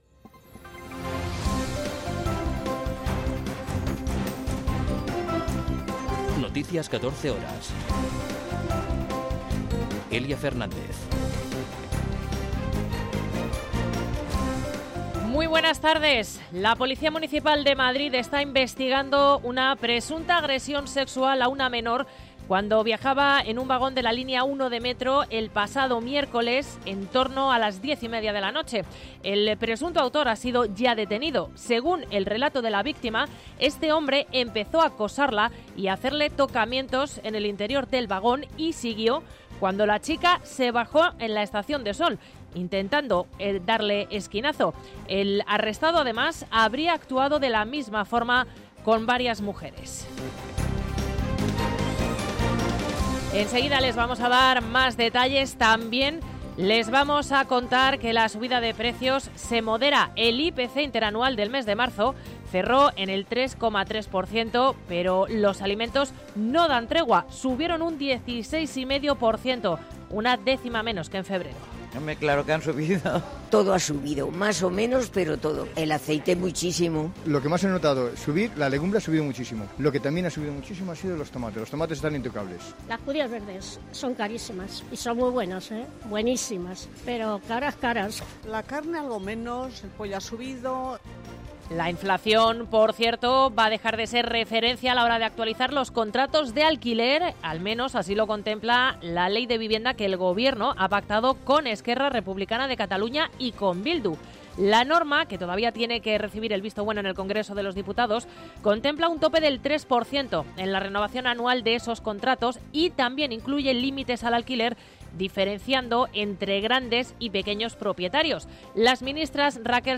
Noticias 14 horas 14.04.2023